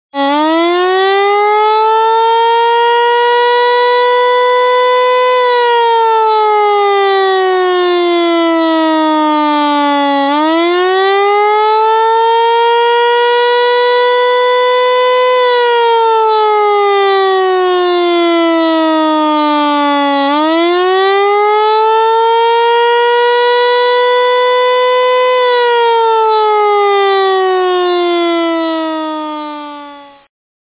Emergency Sirens and Siren Testing
Volume warning: Please note the sample sounds may be loud.
• If you hear a 3-minute slow wail tone, there might be a tsunami.
eclipse_wail.mp3